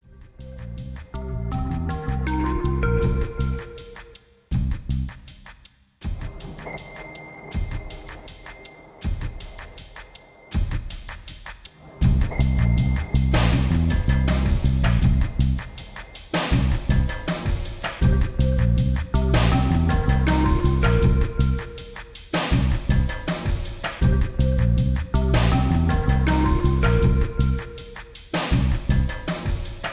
ambient background music